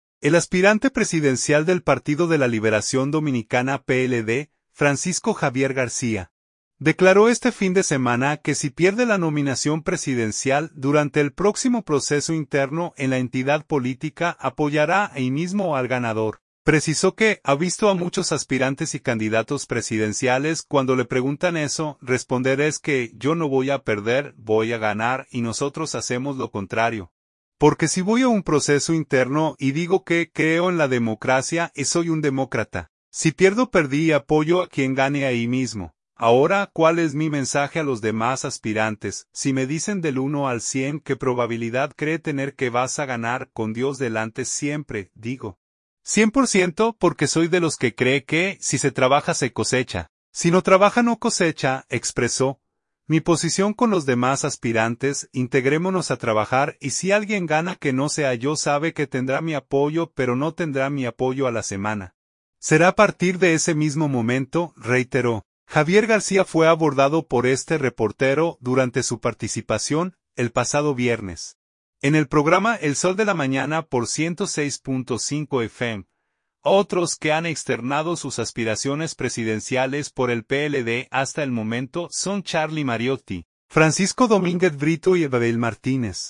Javier García fue abordado por este reportero durante su participación, el pasado viernes, en el programa “El Sol de la Mañana” por 106.5 FM.